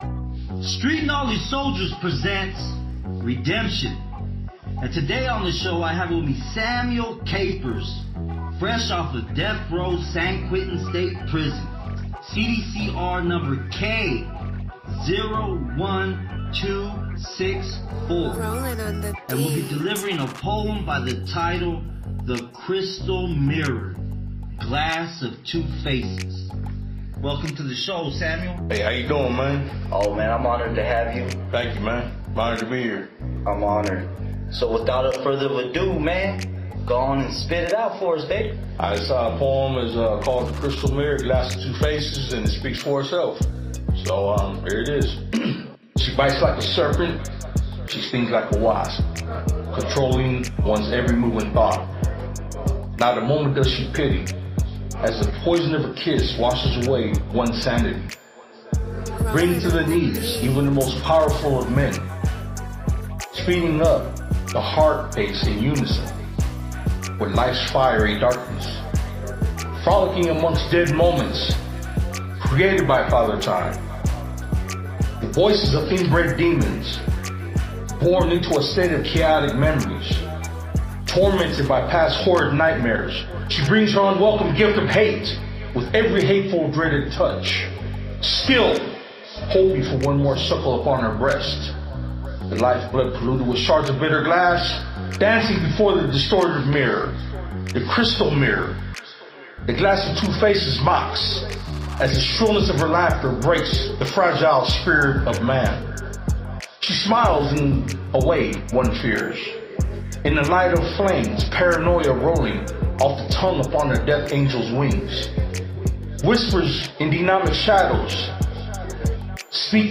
This Is A Testimonial PodCast That Is Built To Bring Forth The Value Of REDEMPTION.